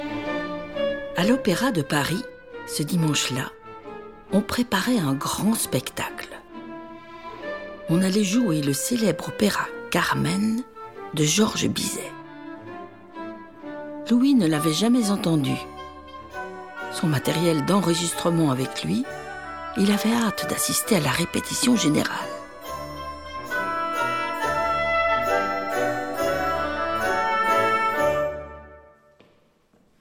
Livre CD
"templateExpression" => "Contes musicaux non classiques"